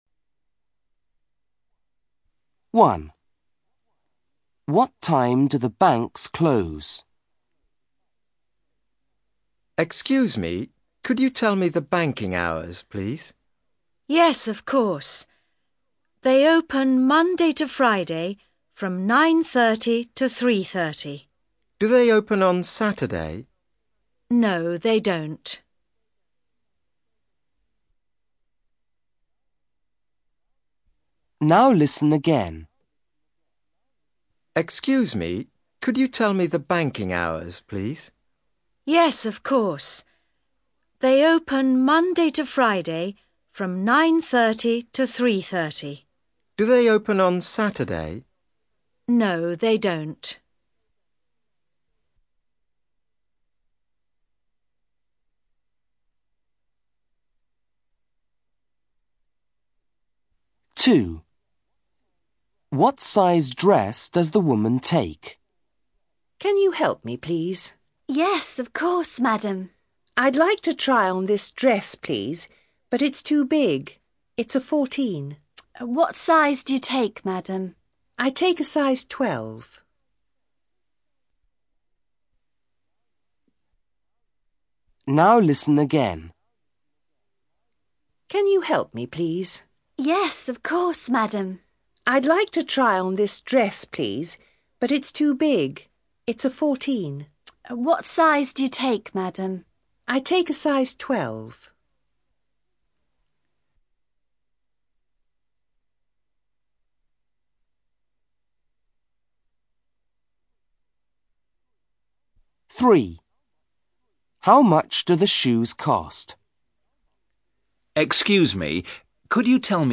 Five short conversations Index BLOG Five short conversations You'll hear five short conversations. You will hear each conversations twice.